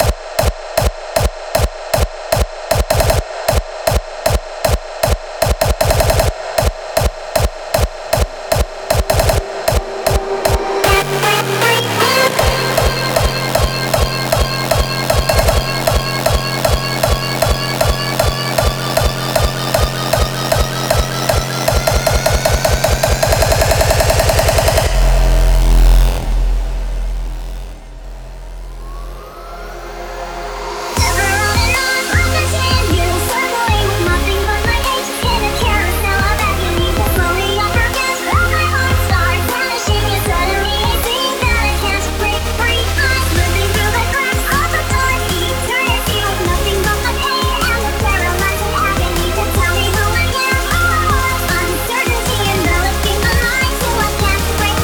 • Категория:Hardstyle